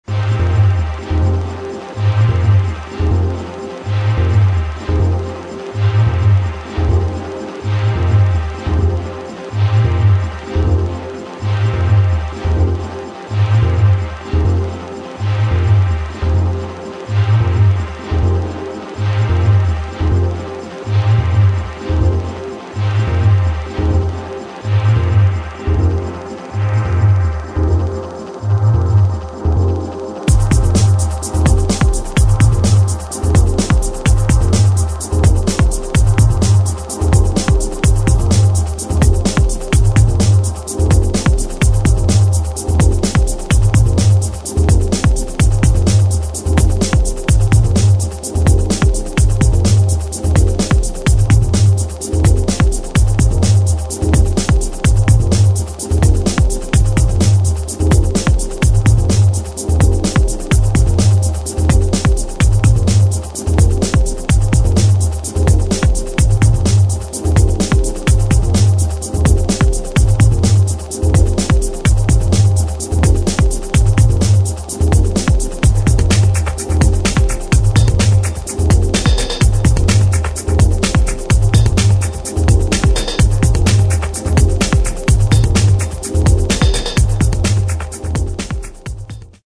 TECH HOUSE | TECHNO | BASS
90年代初期レイヴ感溢れるシングル！！